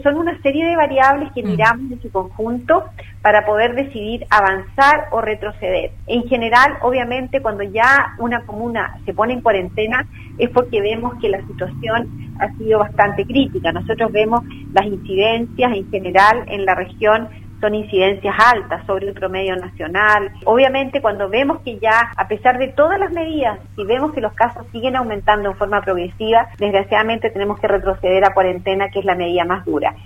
En conversación con Radio Sago, la Subsecretaria de Salud, Paula Daza, abordó la situación epidemiológica y de la red asistencial en la región de Los Lagos.